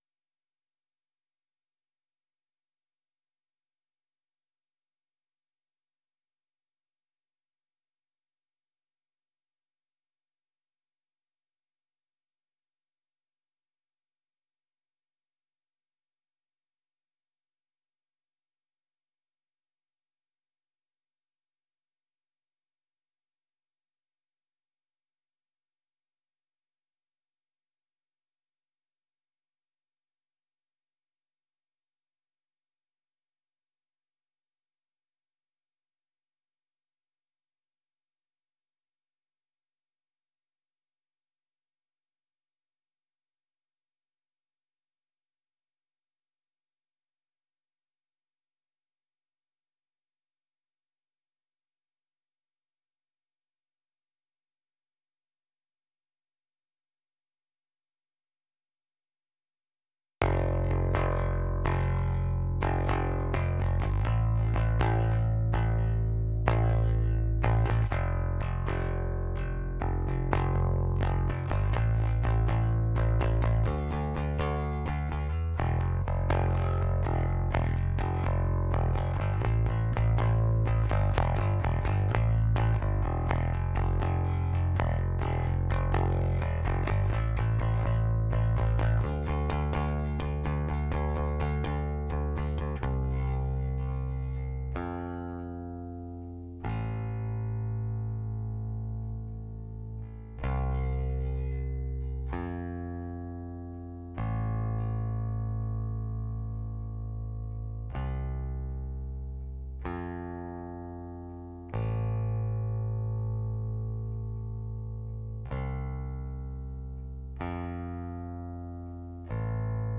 White BasDI.wav